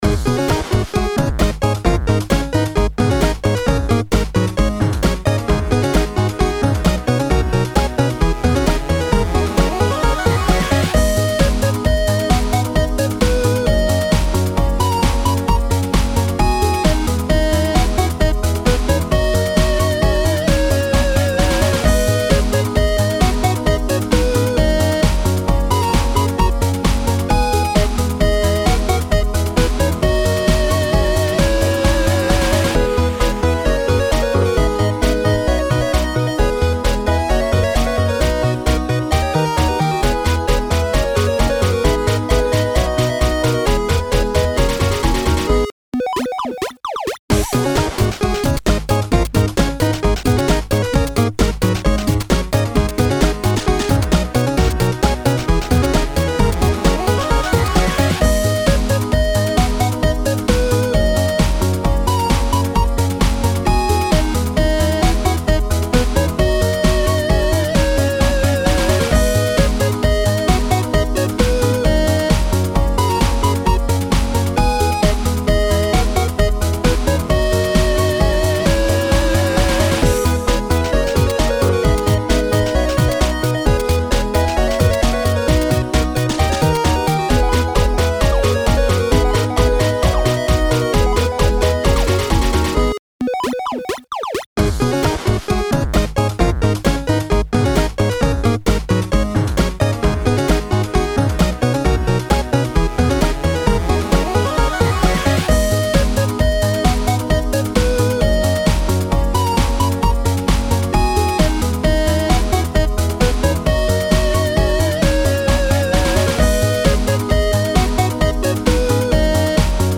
あかるい かっこいい FREE BGM
Download 10分ループVer.